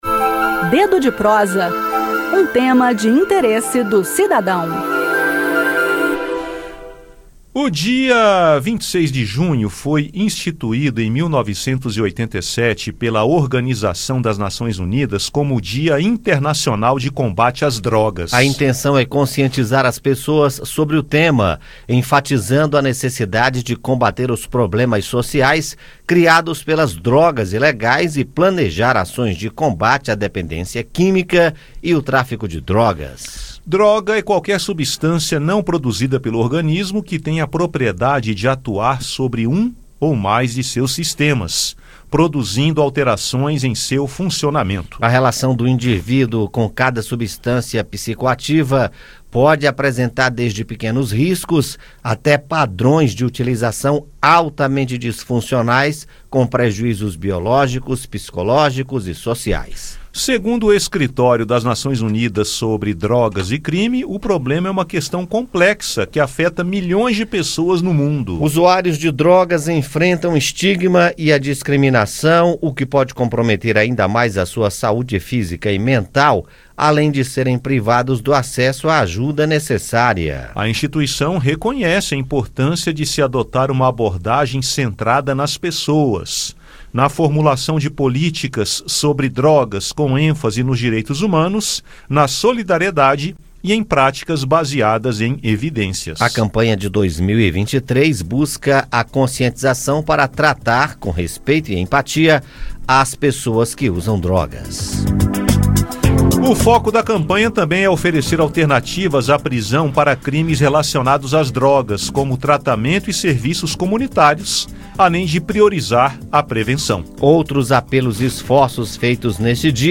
A campanha enfatiza o combate aos problemas sociais criados pelas drogas ilegais, além de ações contra a dependência química e o tráfico de drogas. A iniciativa também faz um alerta sobre as epidemias de aids, hepatite e transtornos associados ao uso de drogas, tratamentos disponíveis, importância da intervenção e do apoio precoce. Ouça no bate-papo.